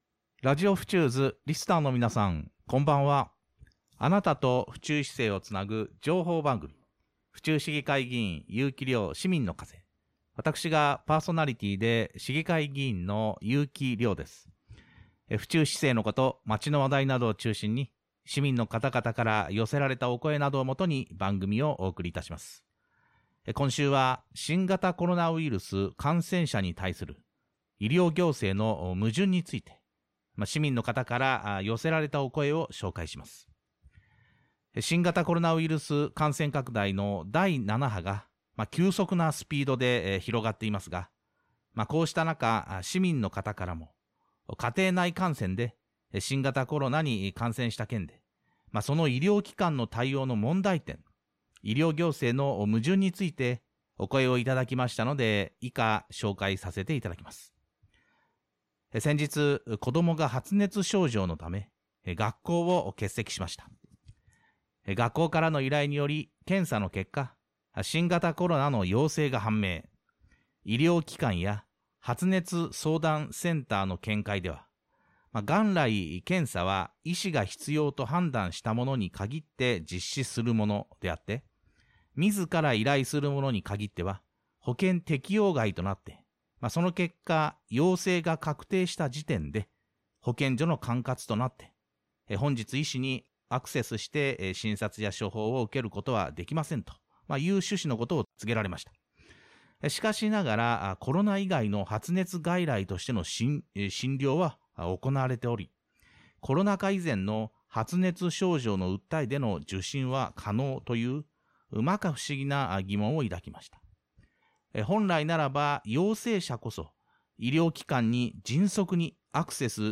ラジオフチューズ（FM府中　８７．４MH）ゆうきりょうのラジオパーソナリティ番組「ゆうきりょうの　市民の風」、毎週火曜日　２２時４５分～６分間放送中、　毎週日曜日も再放送